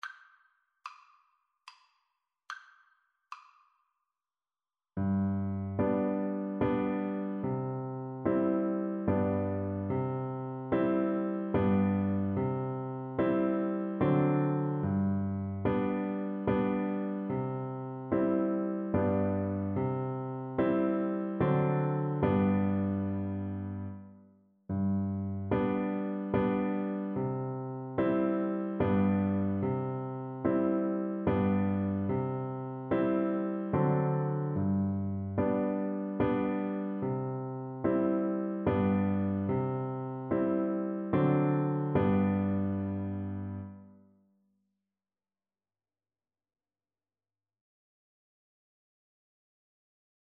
Traditional Trad. Dear Liza (hole in my bucket) Soprano (Descant) Recorder version
Recorder
3/4 (View more 3/4 Music)
G major (Sounding Pitch) (View more G major Music for Recorder )
Traditional (View more Traditional Recorder Music)